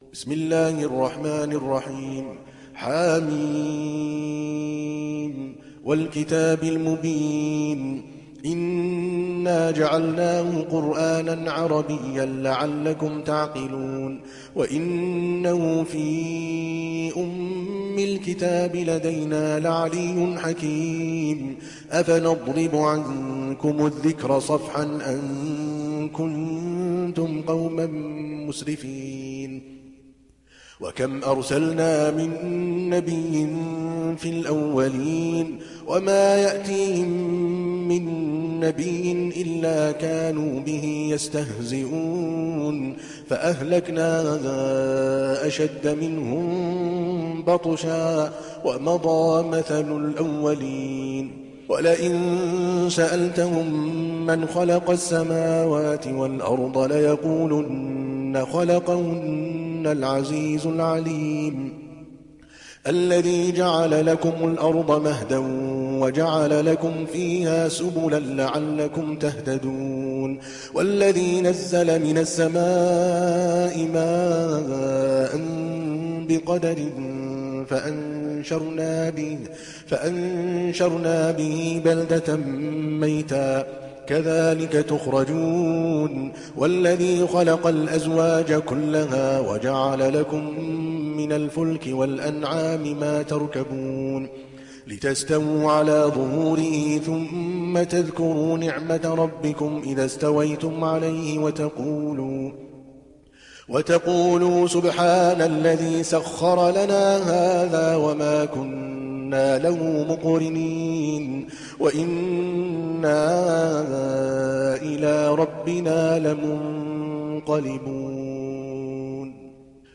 تحميل سورة الزخرف mp3 بصوت عادل الكلباني برواية حفص عن عاصم, تحميل استماع القرآن الكريم على الجوال mp3 كاملا بروابط مباشرة وسريعة